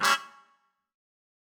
GS_MuteHorn-Gdim.wav